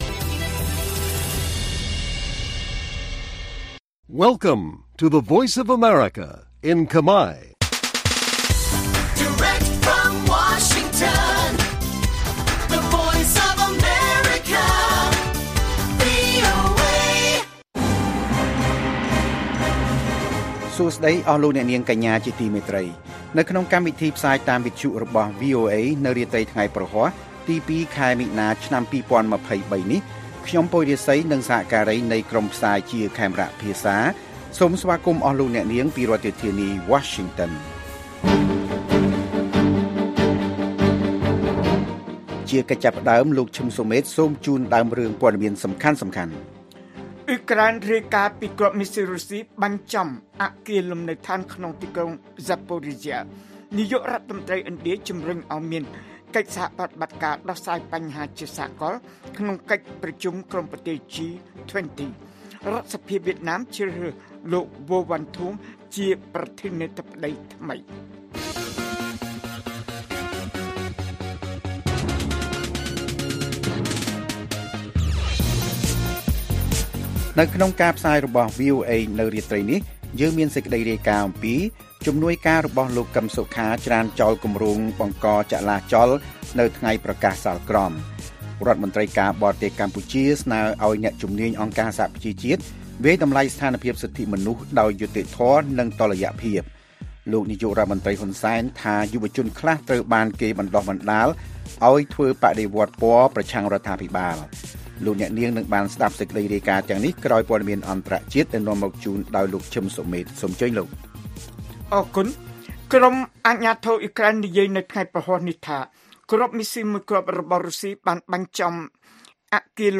ព័ត៌មានពេលរាត្រី ២ មីនា៖ អ៊ុយក្រែនរាយការណ៍ពីគ្រាប់មីស៊ីលរុស្ស៊ីបាញ់ចំអគារលំនៅដ្ឋានក្នុងទីក្រុង Zaporizhzhia